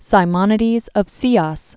(sī-mŏnĭ-dēz; sēŏs) 556?-468?